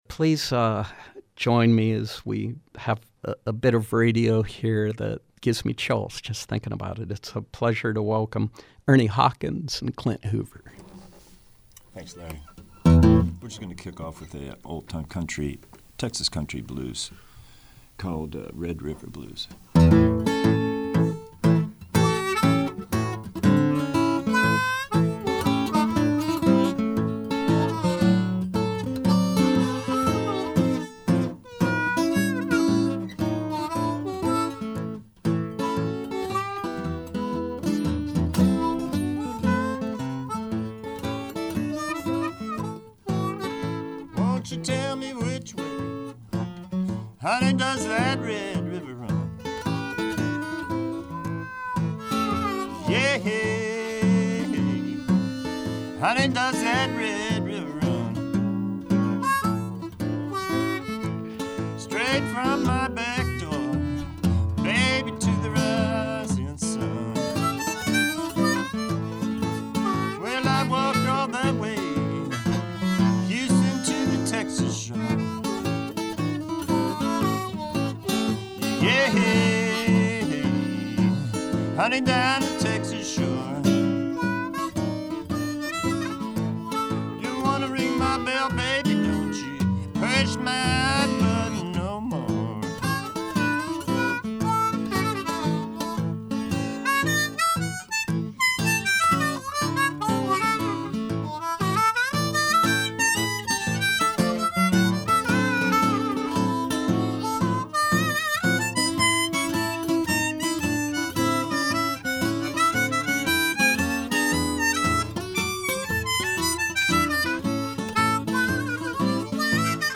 Live music
acoustic blues guitarist
harp player